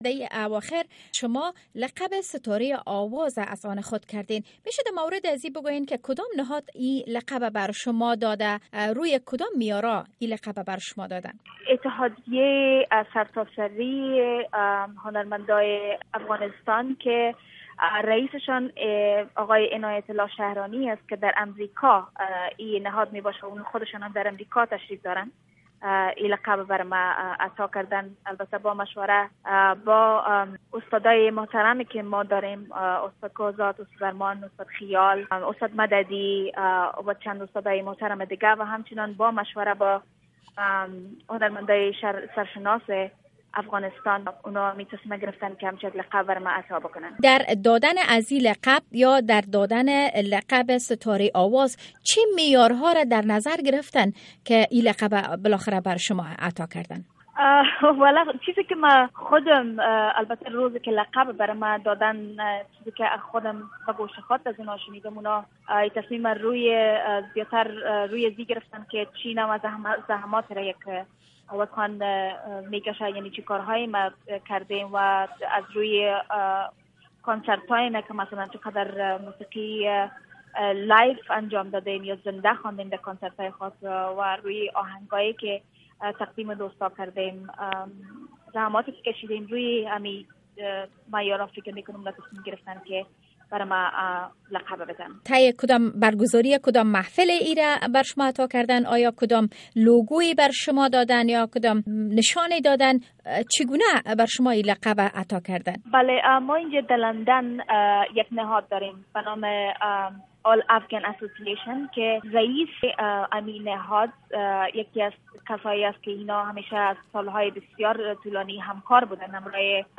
interview with Ariana Sayed